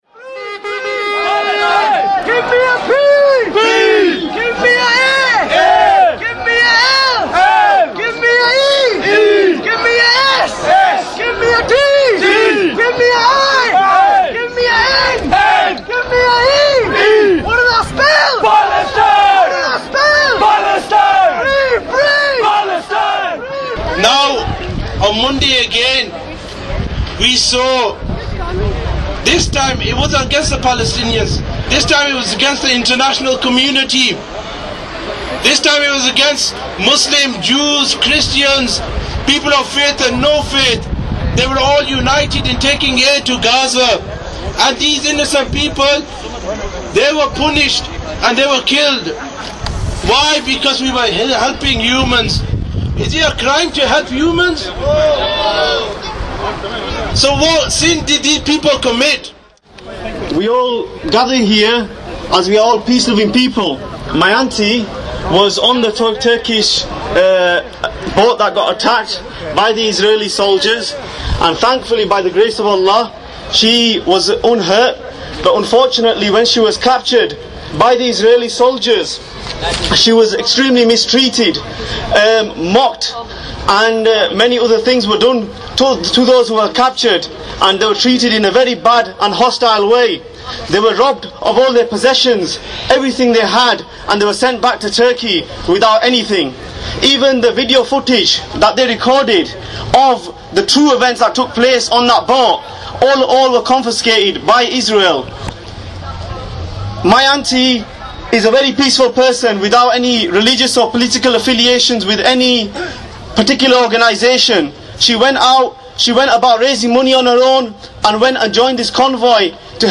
The march was a loud one with much chanting as it snaked through the streets of shops.
Sound Audio recording from the demo in Bradford